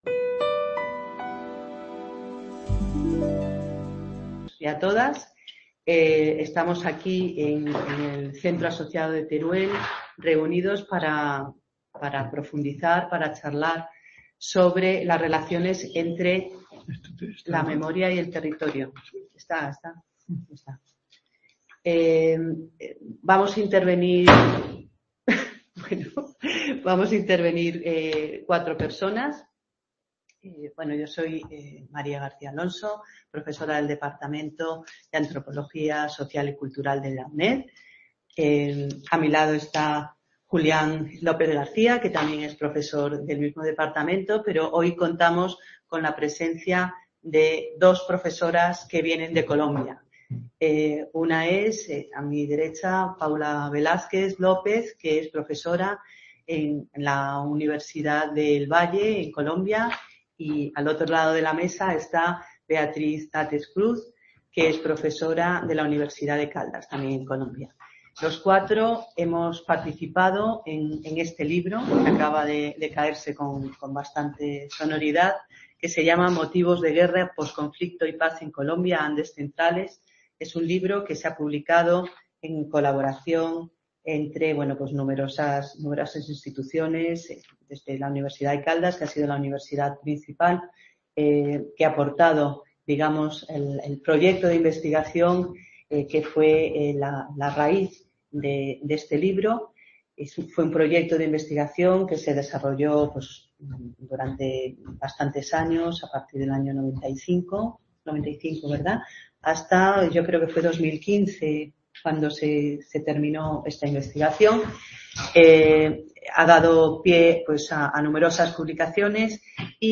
Seminario de reflexión sobre la territorialización de la memoria colectiva en momentos de conflicto y sus modificaciones en tiempos de paz.